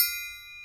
TSW TRIANGLO.wav